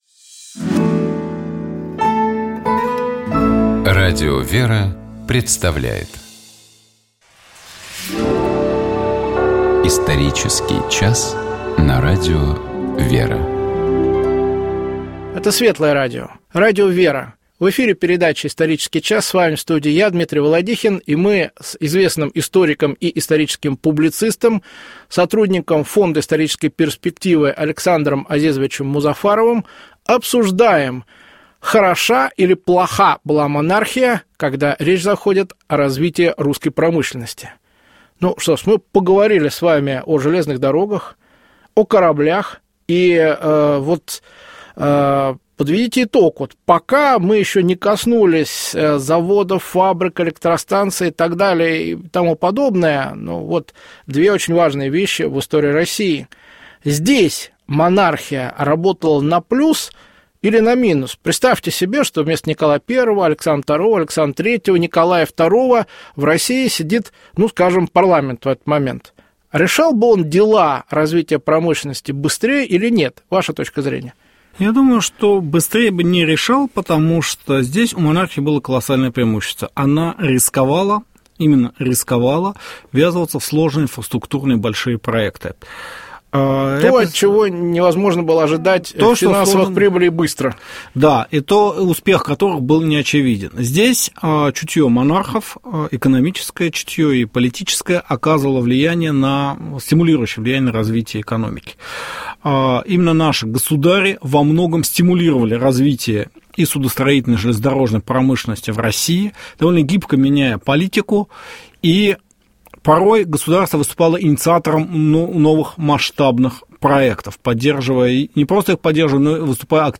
Разговор шел о развитии экономики в Российской Империи при последнем русском императоре, о том, каких успехов удалось достичь, и какова была роль в этом лично Николая II.